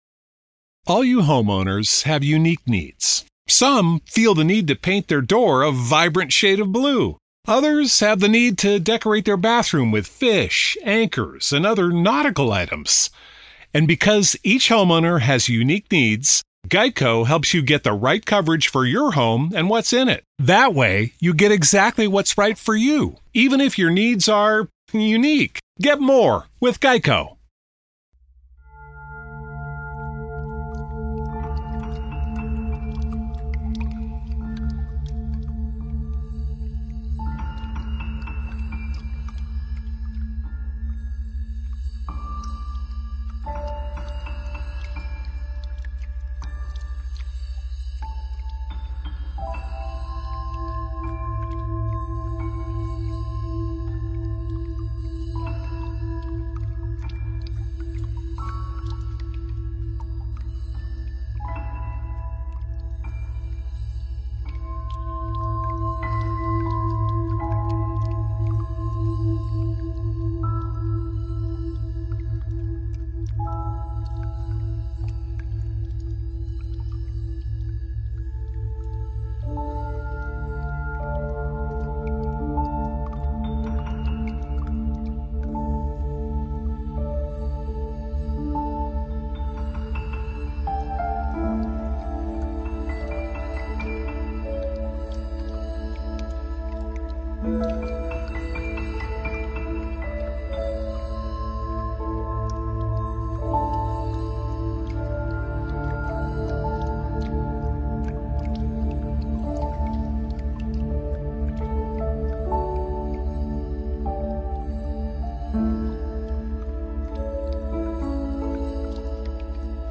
Hypnosis and relaxation ｜Sound therapy